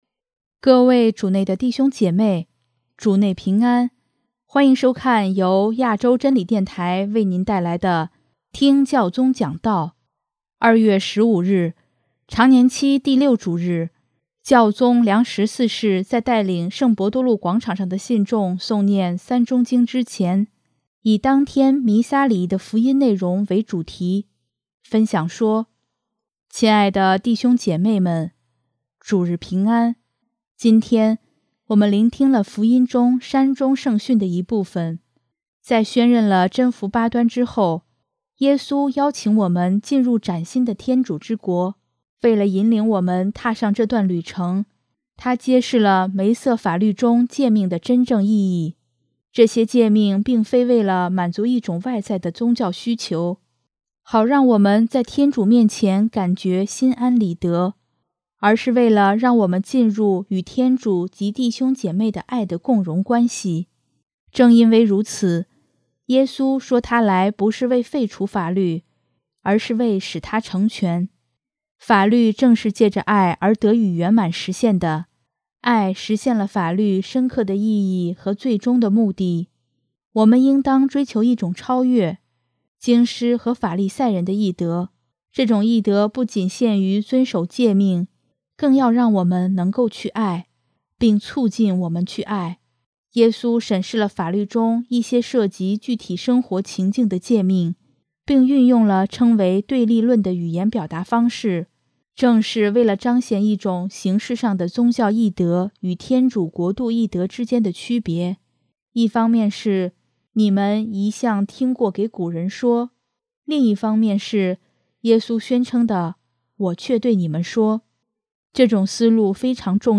2月15日，常年期第六主日，教宗良十四世在带领圣伯多禄广场上的信众诵念《三钟经》之前，以当天弥撒礼仪的福音内容为主题，分享说：